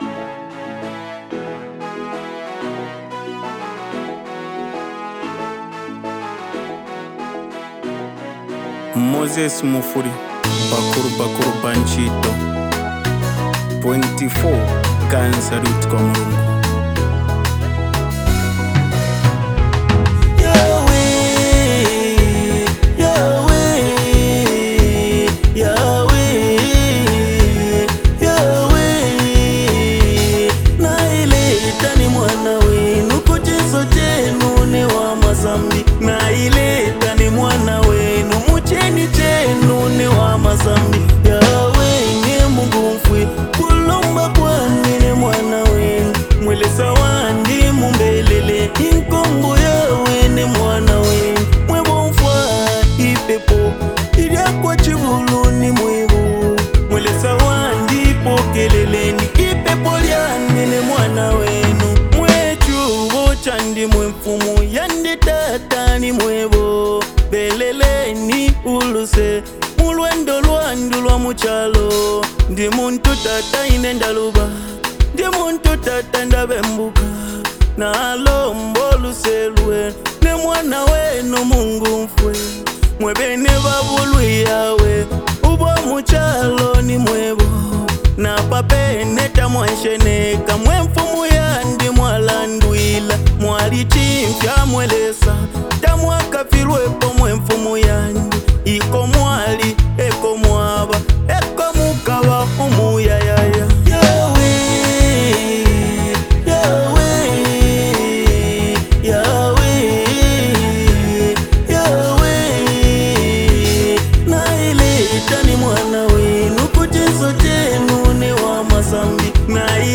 Genre: Afro-Beats, Gospel Song